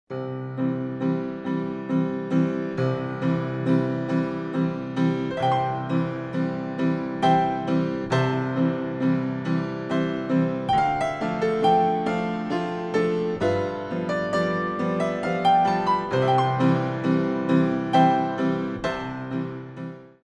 Waltz- Adagio
for Port de Bras